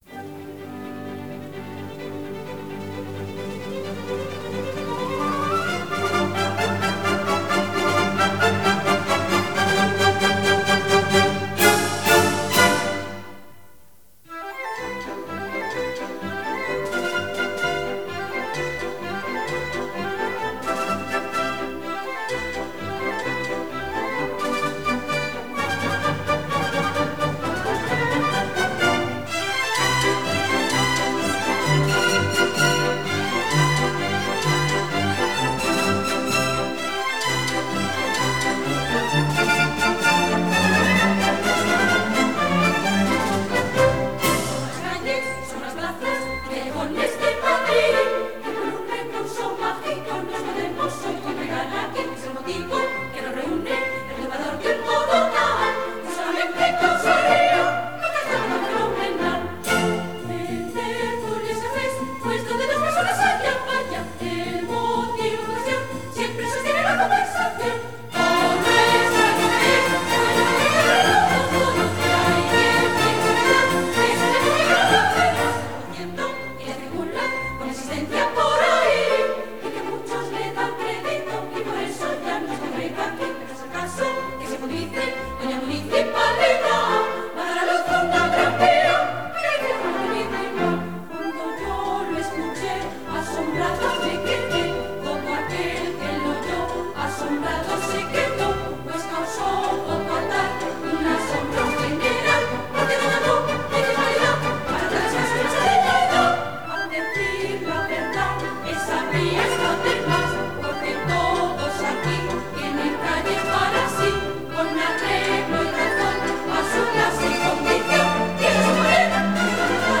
Canto
Ópera
Orquesta